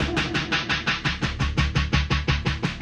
RI_DelayStack_85-01.wav